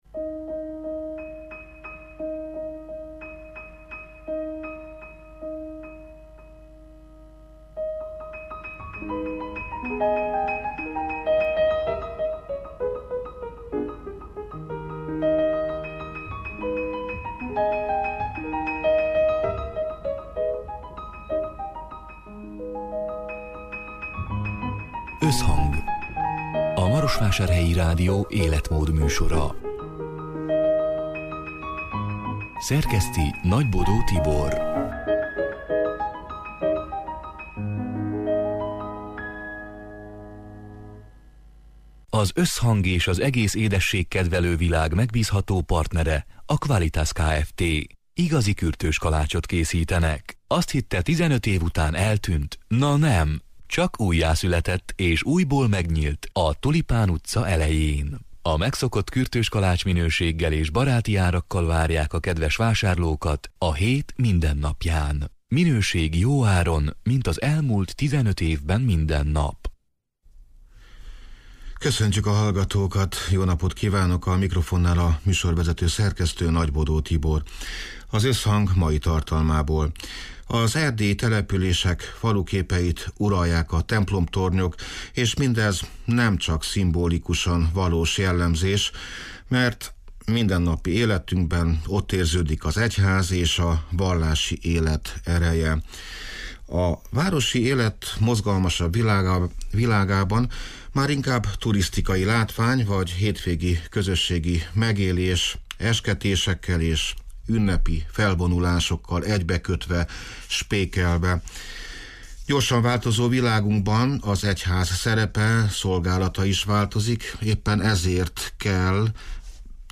A Marosvásárhelyi Rádió Összhang (elhangzott: 2022. április 20-án, szerdán délután hat órától élőben) c. műsorának hanganyaga: Erdélyi települések faluképeit uralják a templomtornyok, és mindez nemcsak szimbolikusan valós jellemzés, mert mindennapi életünkben ott érződik az egyház, a vallá